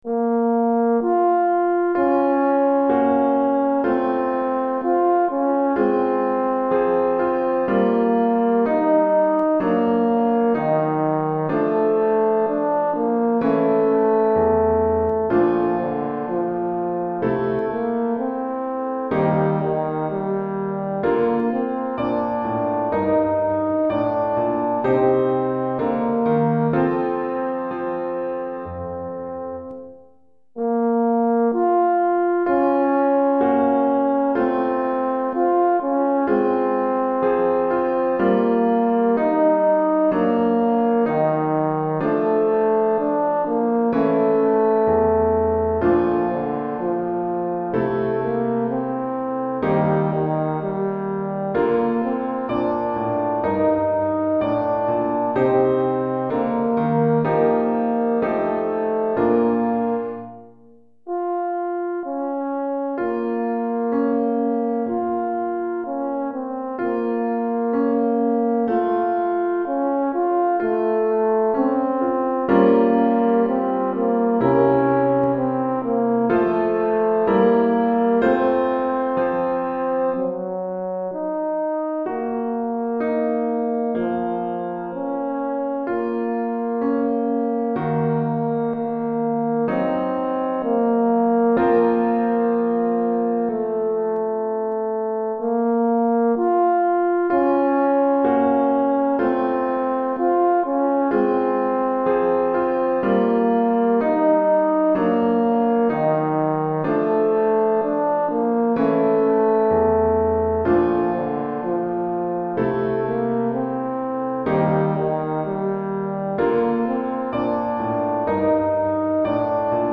Cor en Fa et Piano